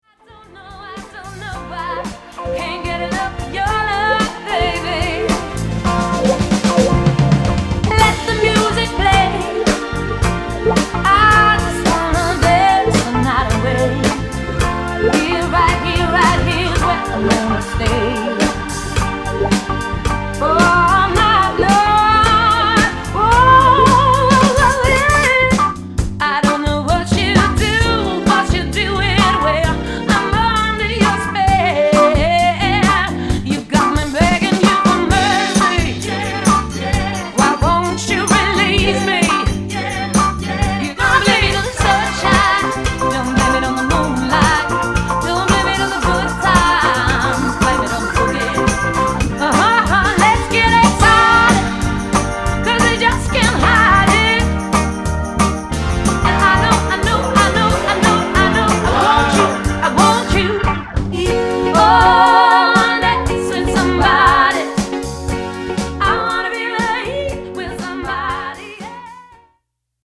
På jakt etter coverband?
i tillegg til bandets faste kvinnelige vokalist